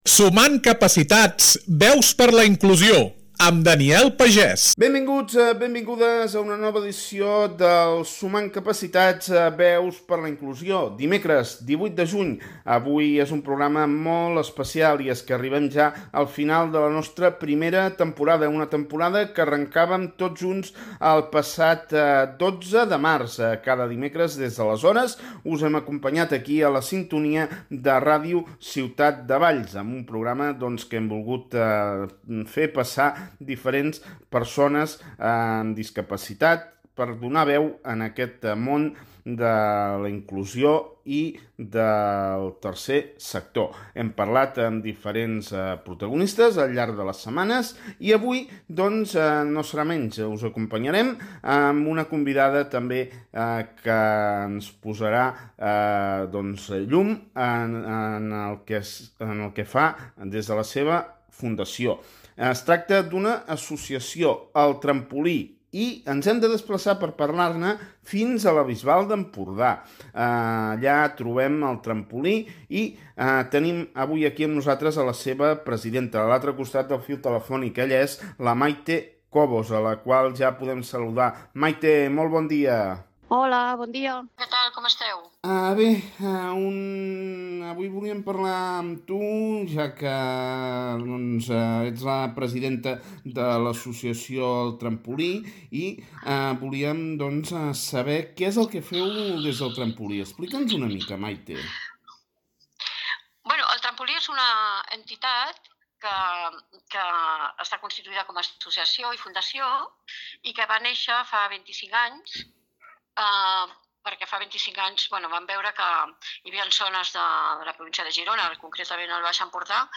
Un espai d’entrevistes sobre el món de la discapacitat, la inclusió i el Tercer Sector.